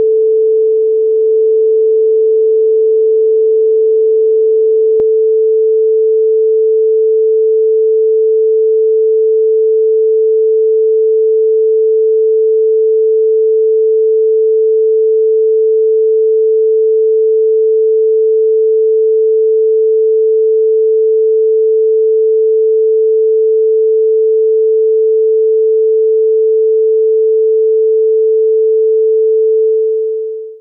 锯齿机
描述：在带锯中用曲柄冲床打出齿位的机器，同时带卷在前进中。有一个停顿，没有打孔。这台机器每分钟要打几百个齿。用Rode NT4话筒和Edirol R44录音机录制。
标签： 工厂 自动 锯齿形
声道立体声